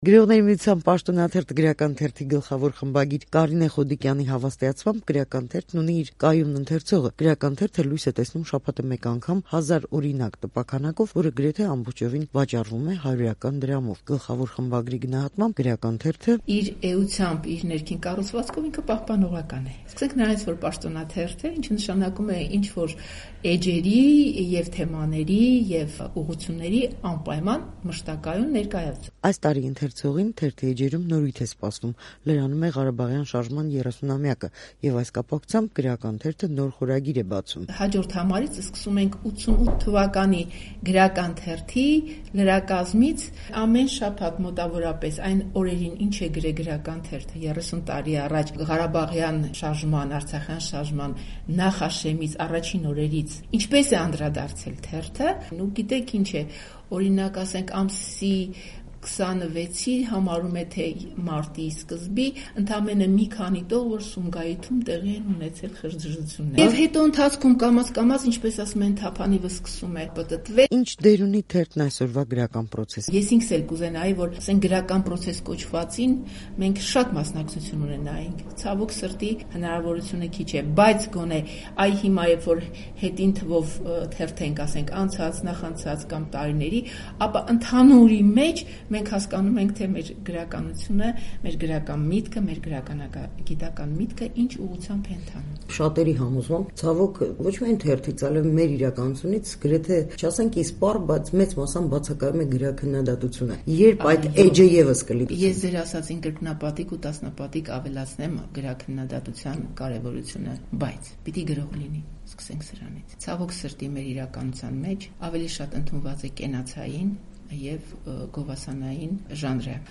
հարցազրույցն